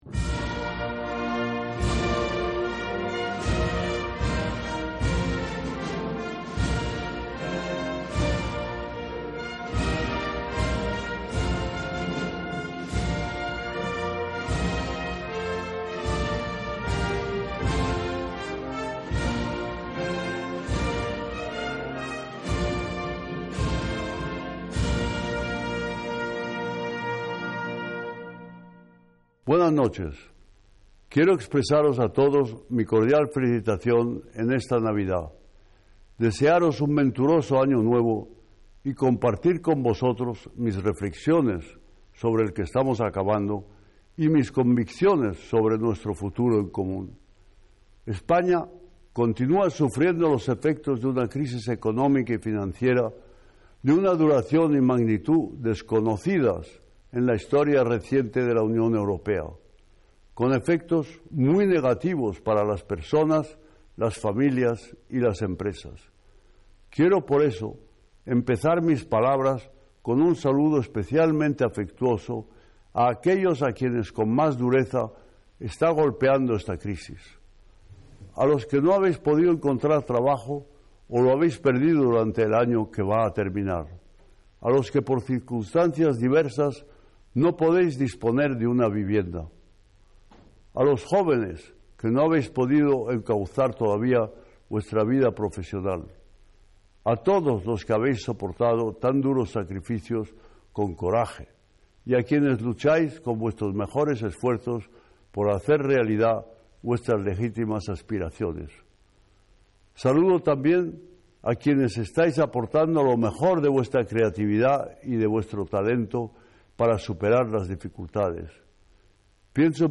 Detalle Discurso - Mensaje de Navidad de Su Majestad el Rey